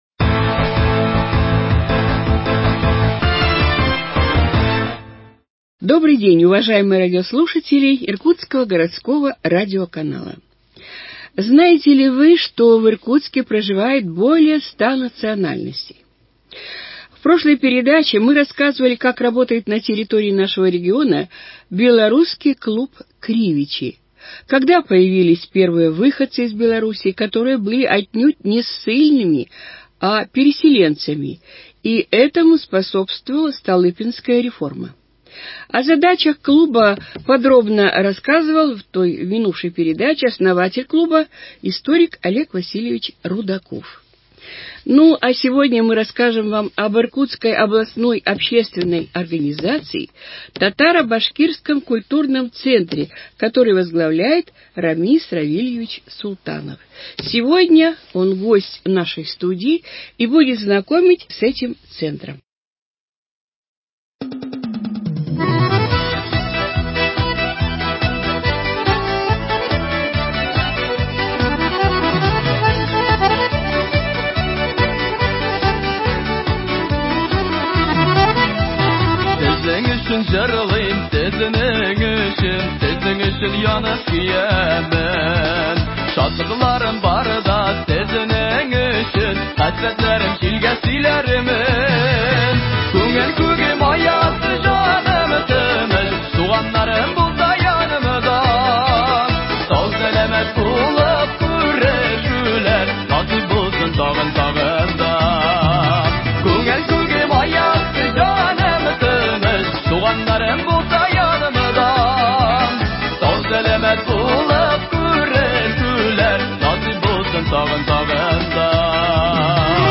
Актуальное интервью: Татаро-башкирский центр 05.11.2020